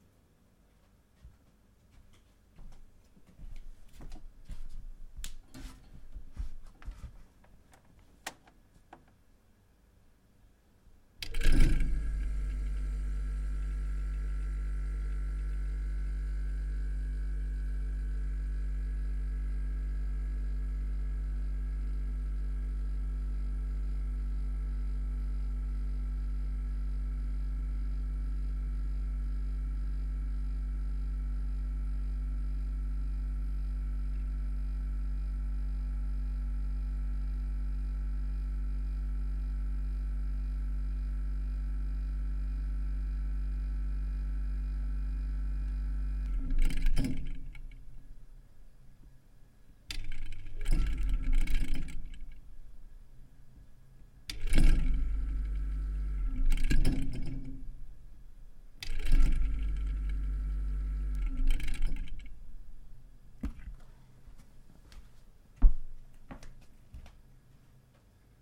冰箱 " 轨道(3)
描述：旧苏联冰箱。
Tag: 厨房 冰箱 电机 冰箱 房子记录 国内 发动机